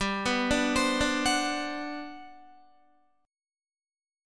GM11 Chord
Listen to GM11 strummed